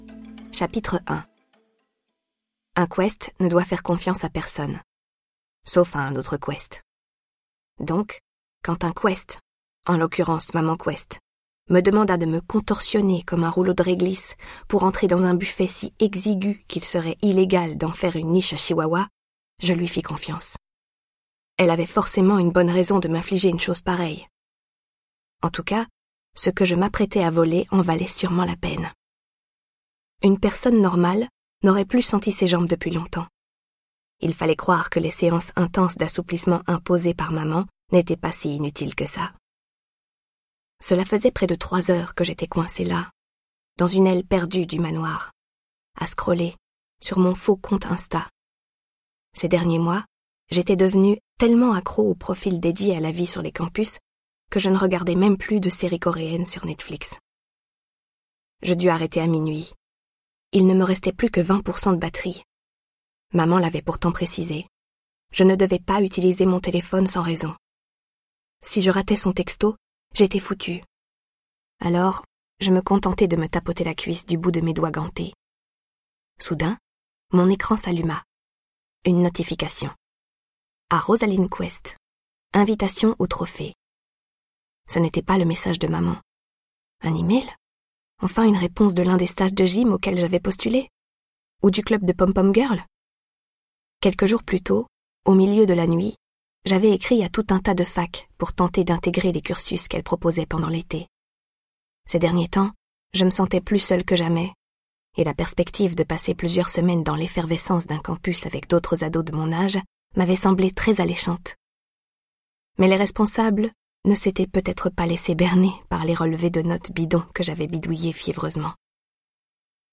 Sa lecture dynamique vous plonge dans un monde où la concurrence fait rage.